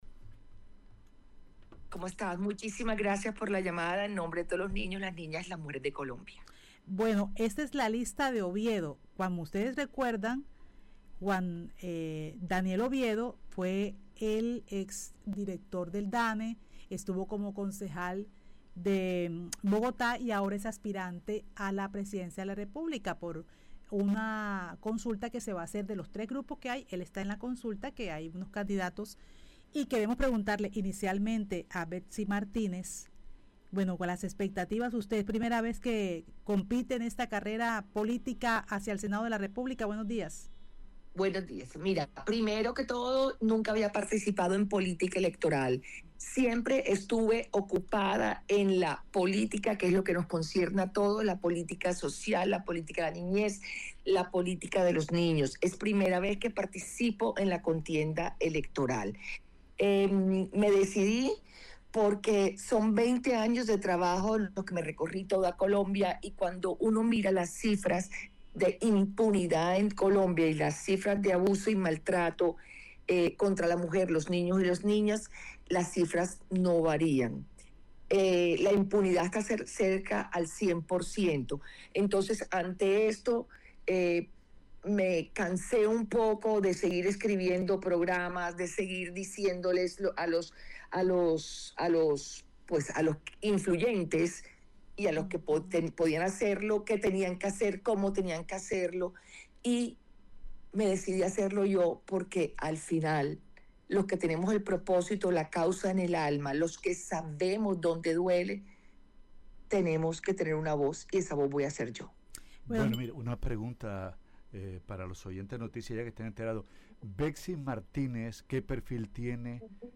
En diálogo con Noticias Ya